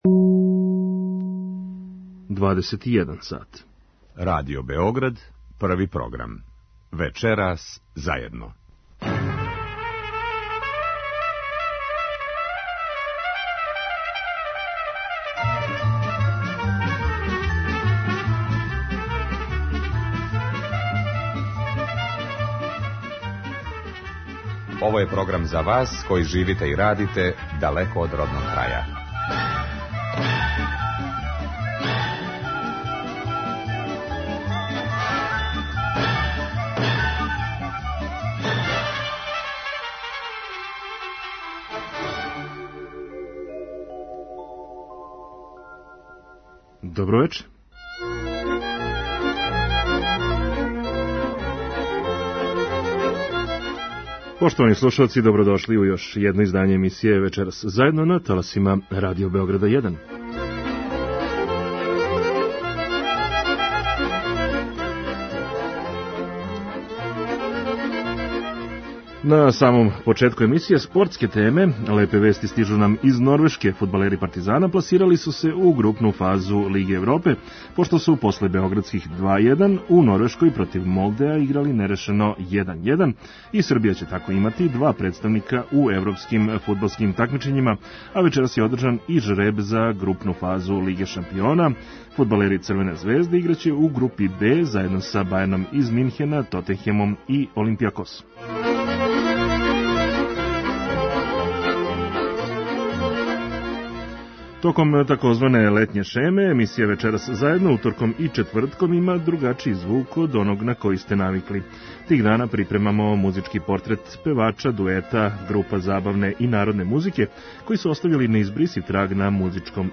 Тих дана припремамо музички портрет певача, дуета , група забавне и народне музике који су оставили неизбрисив траг на музичком небу Србије. Многа извођења која ћете чути чувају се као трајни записи у богатој Фонотеци Радио Београда.